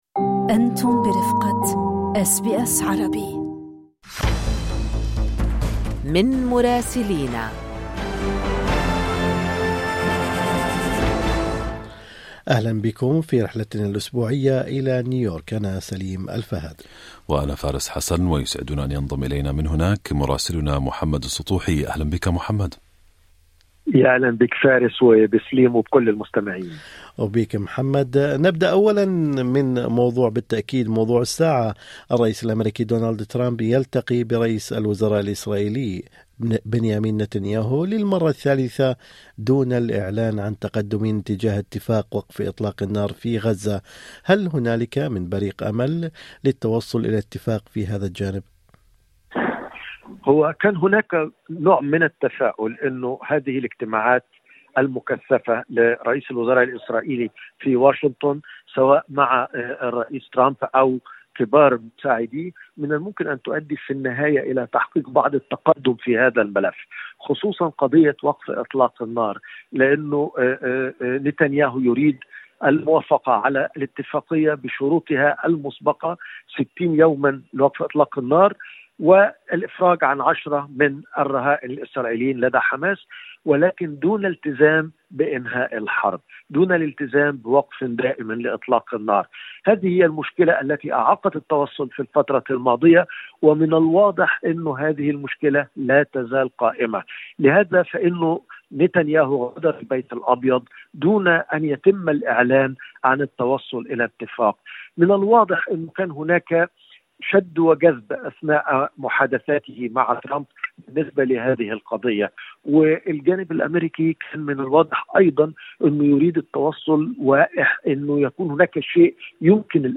تقرير المراسل